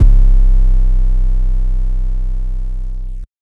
808 (Southside).wav